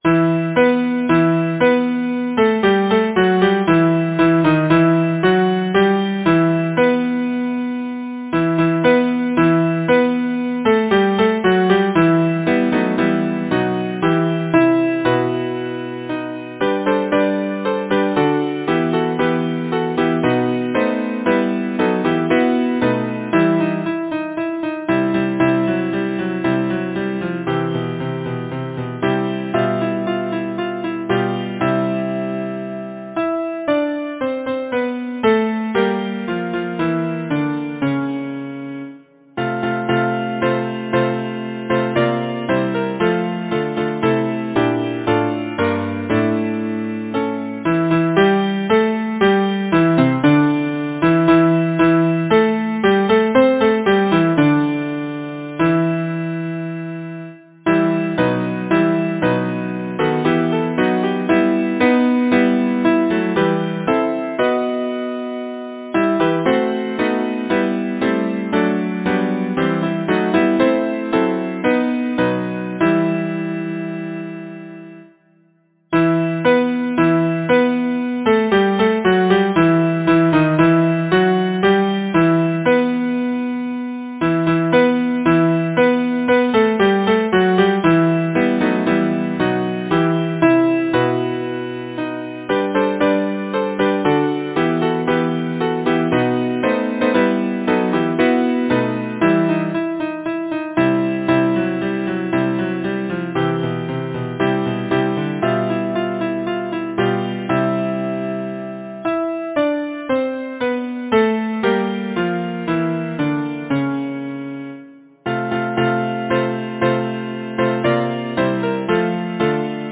Title: The Douglas Raid Composer: Oliveria Prescott Lyricist: Anonymous Number of voices: 4vv Voicing: SATB Genre: Secular, Partsong
Language: English Instruments: A cappella